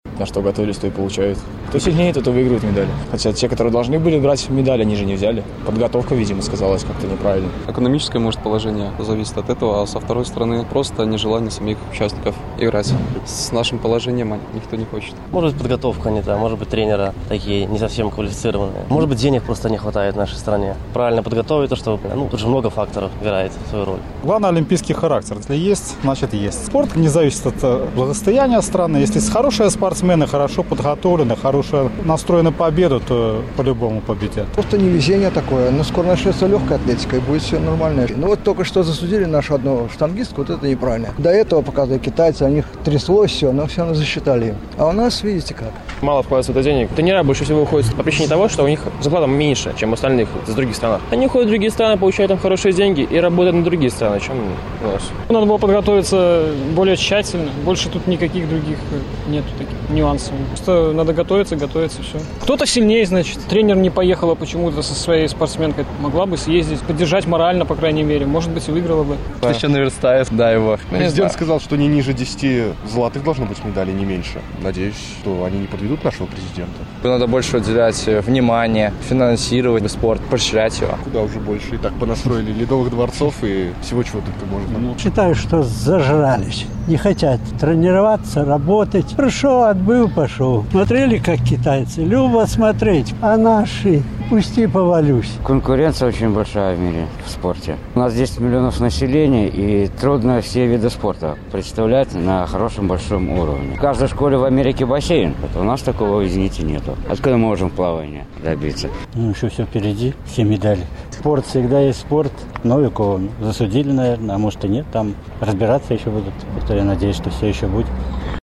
Чаму беларусы няўдала выступаюць на лёнданскай Алімпіядзе? Адказвалі жыхары Магілёва